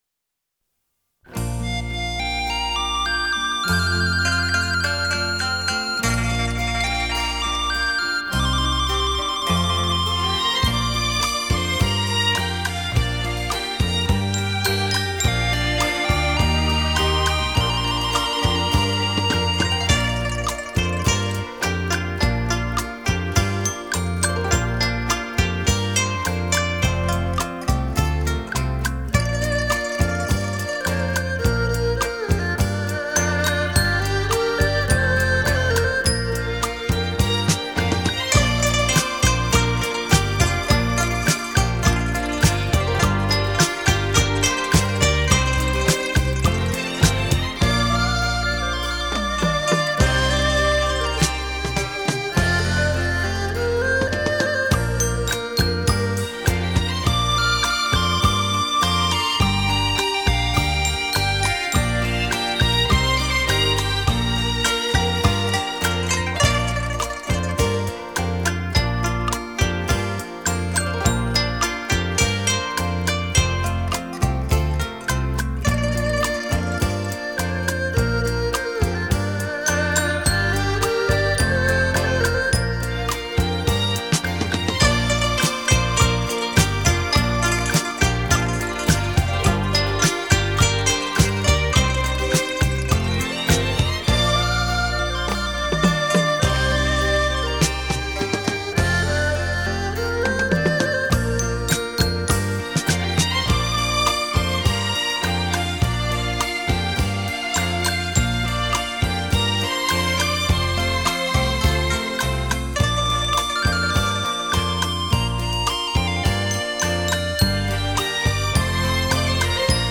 古箏金曲滿天星 特殊演奏傳情意
繞場立體音效 發燒音樂重炫